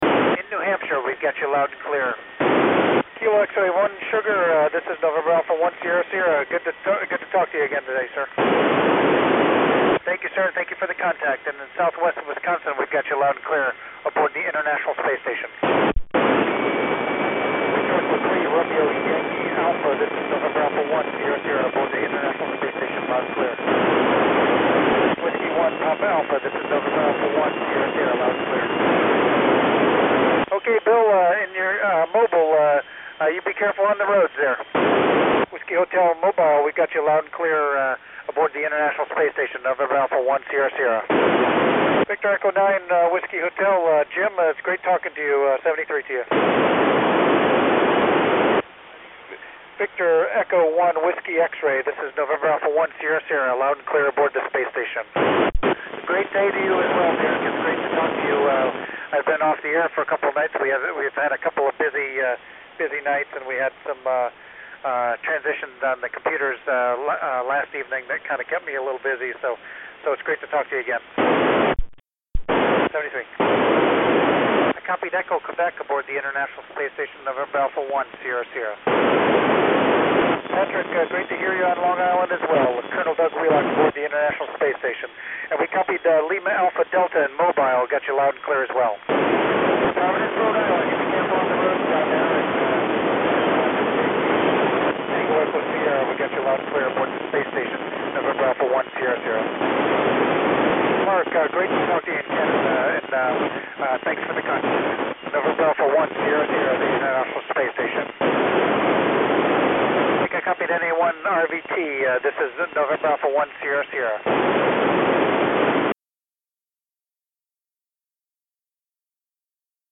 NA1SS RANDOM CONTACTS: July 14 2150 Z